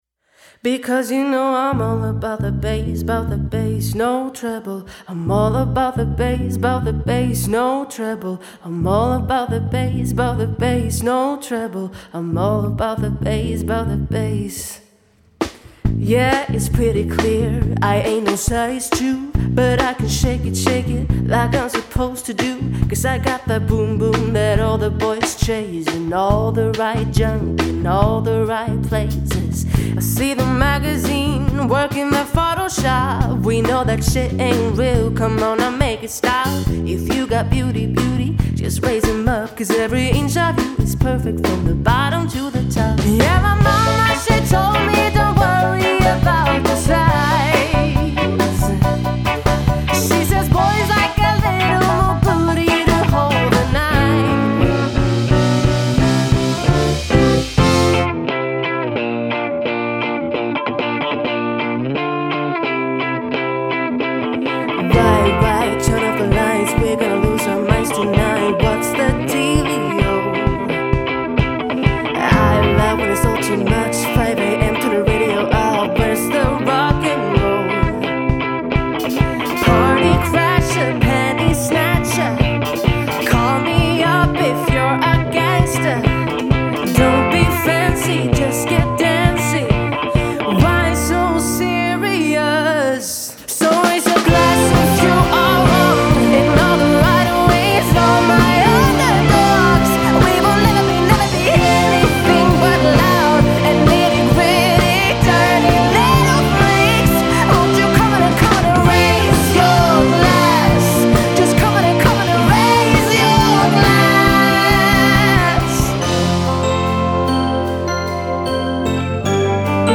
Sång
Piano/keys
Gitarr
Trummor